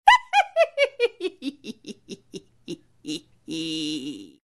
laugh-end.ogg